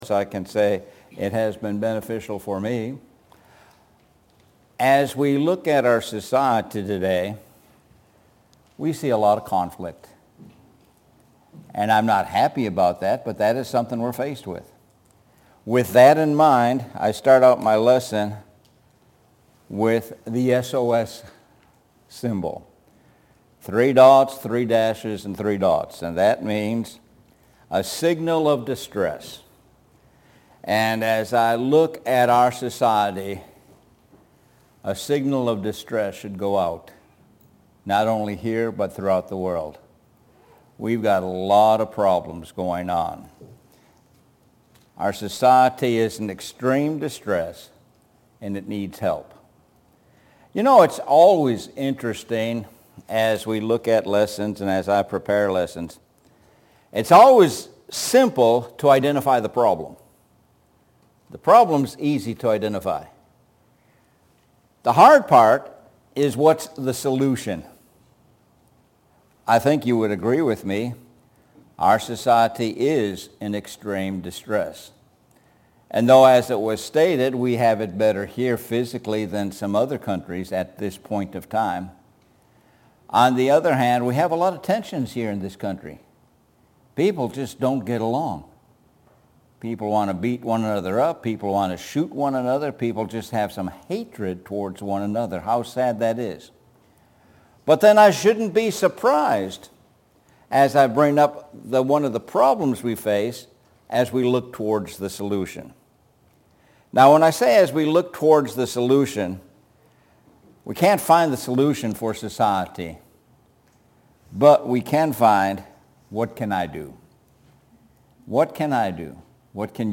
Sun AM Sermon – SOS- Society in Extreme Distress